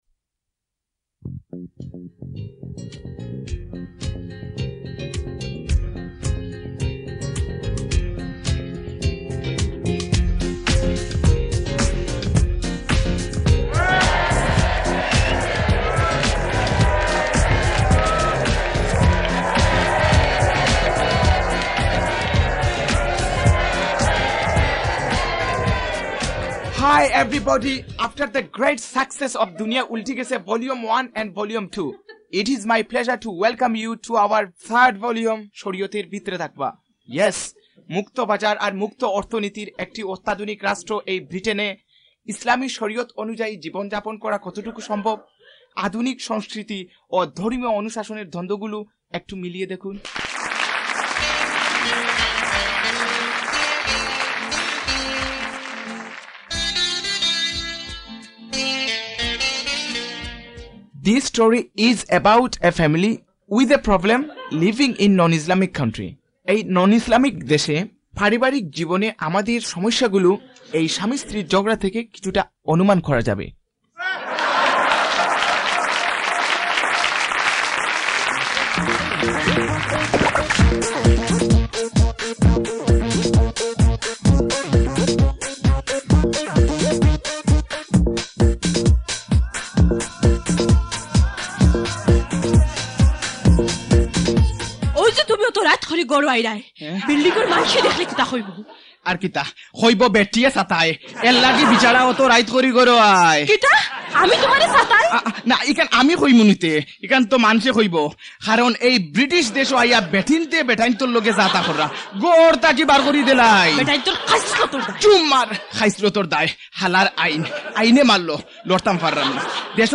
Shoriate Part 1 – Comedy